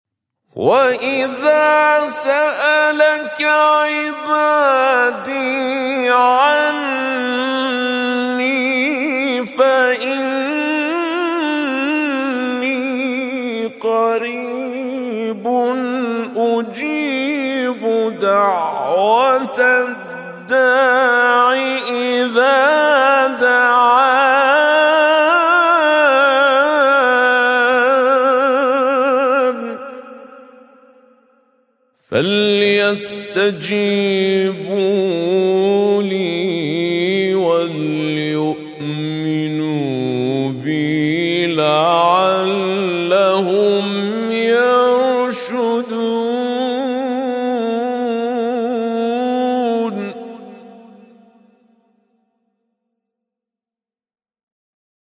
وإذا سألك عبادي عني فإني قريب... - بأسلوب التحقيق